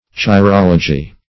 Chirology \Chi*rol"o*gy\, n. [Gr. chei`r hand + -logy.]